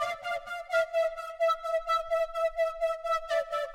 耳环
描述：一个人的耳朵响起的近似声音。它结合了多种色调，创造出更逼真的效果。声音是由我专门创建这个声音的程序生成的。
标签： 高音调
声道立体声